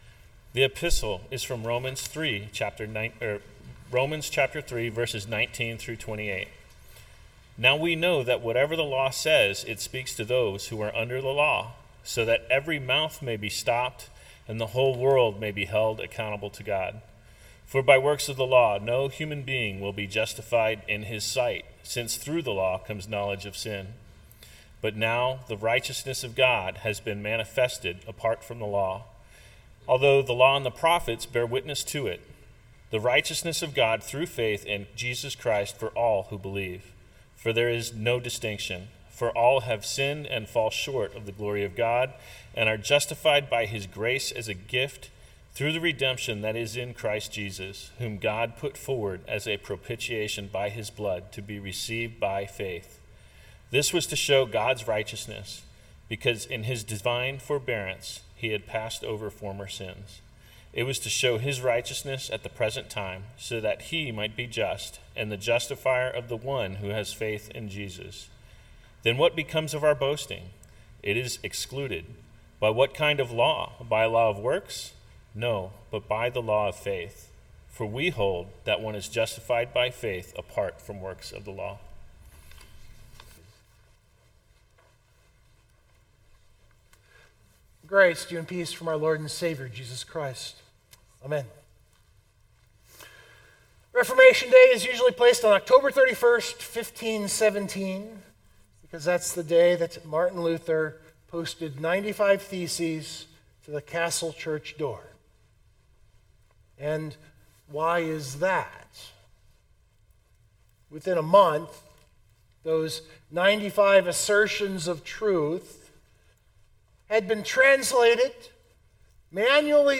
But today was Reformation Day (Observed).